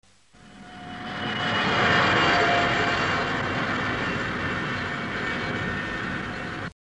Sci Fi Car Drive